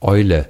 Ääntäminen
Ääntäminen France: IPA: [ʃwɛt] Haettu sana löytyi näillä lähdekielillä: ranska Käännös Konteksti Ääninäyte Adjektiivit 1. klasse 2. toll Substantiivit 3.